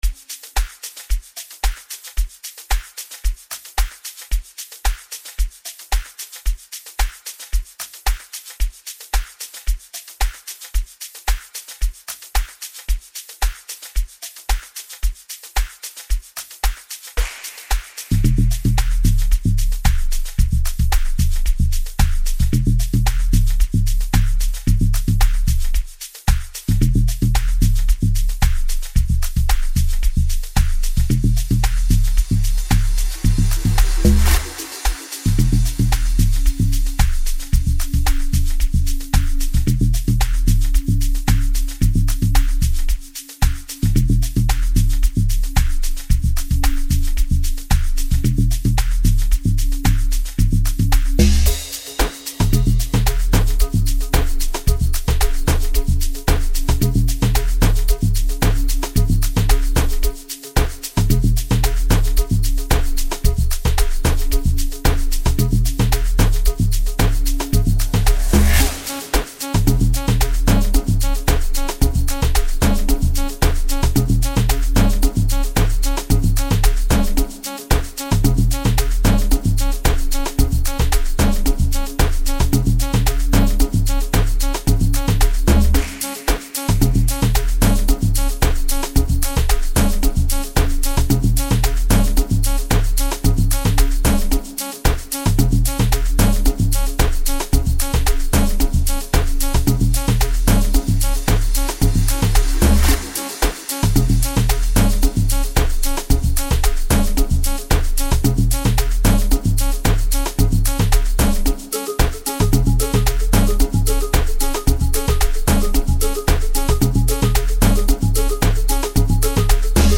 As a dedicated Amapiano producer
an instrumental offering